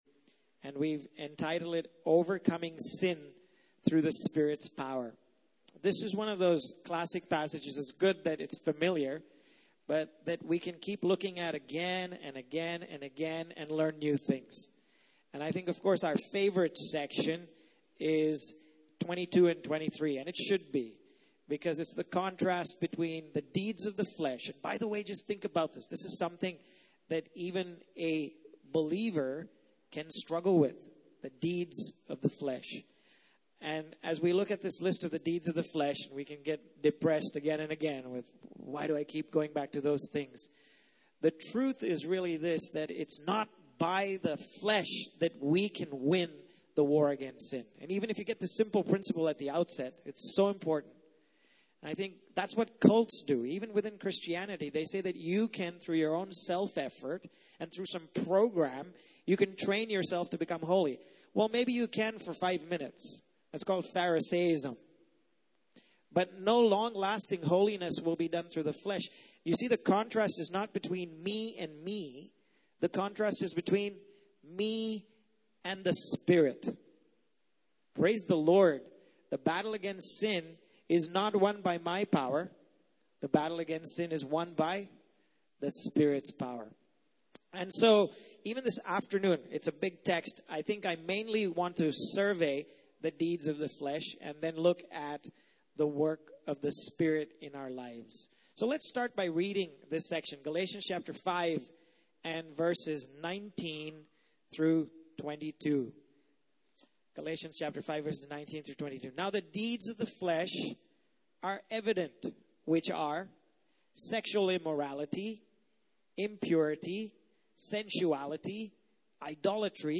Passage: Galatians 5:19-23 Service Type: Main Session